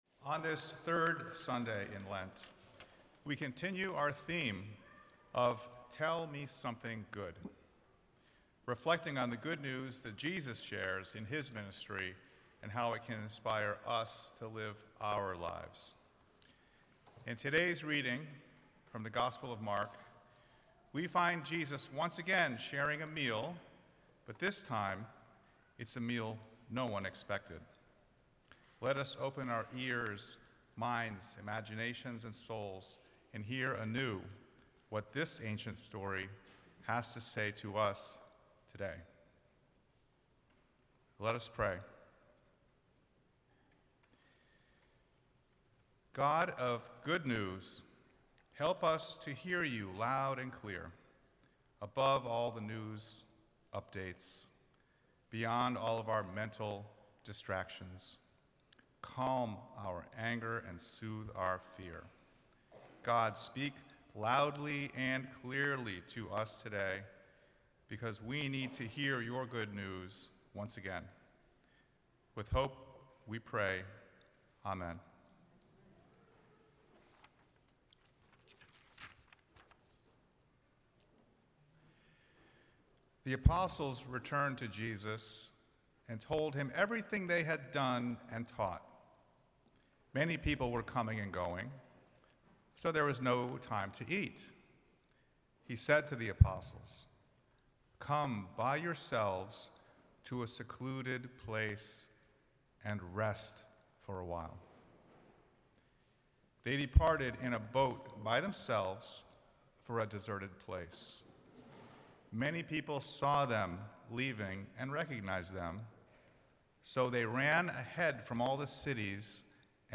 Sunday-Sermon-March-8-2026.mp3